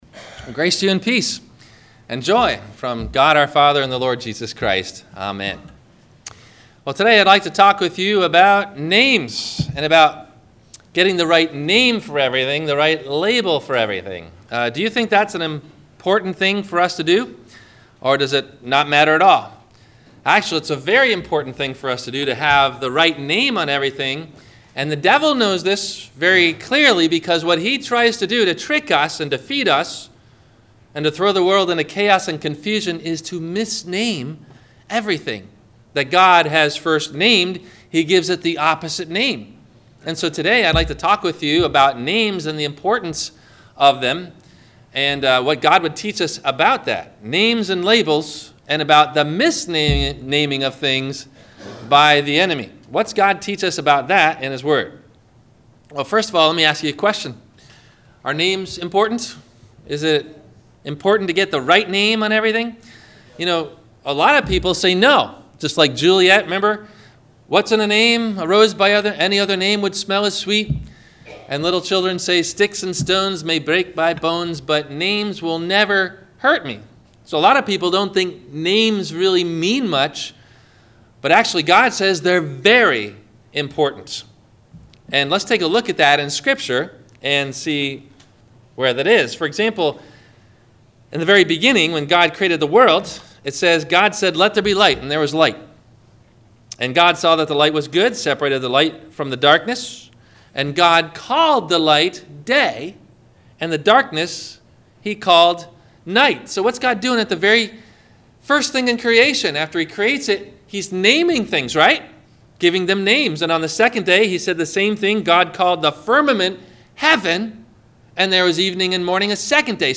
Beware of the Namechanger - Sermon - March 19 2017 - Christ Lutheran Cape Canaveral